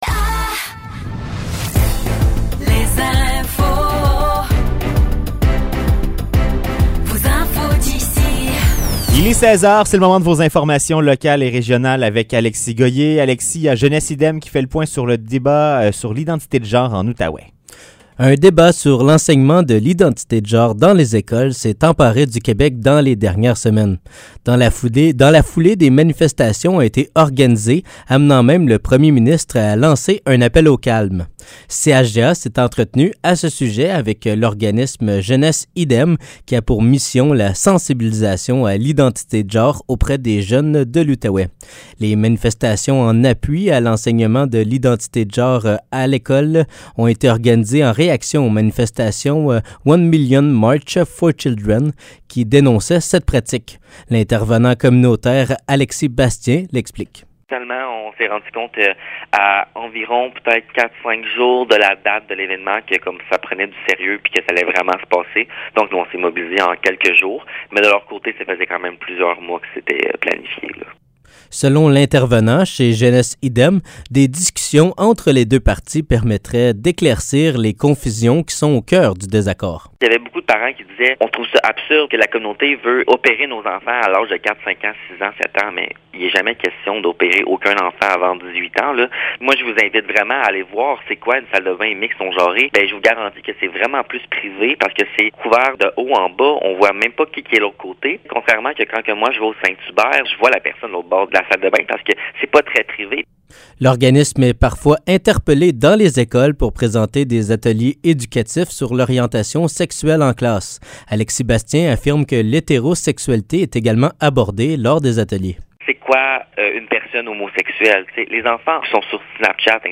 Nouvelles locales - 27 septembre 2023 - 16 h